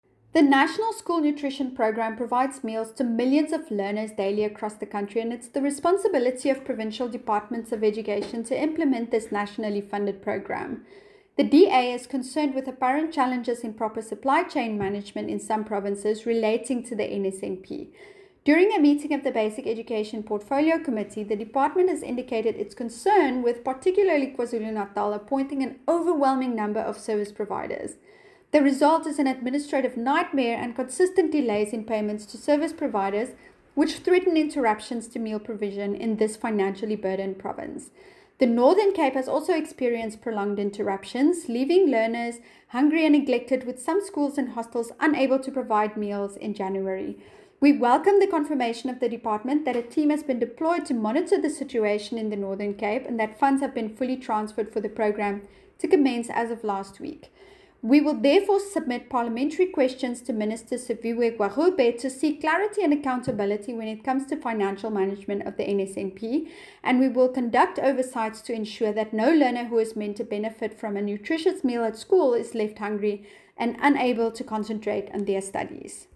soundbite by Ciska Jordaan MP.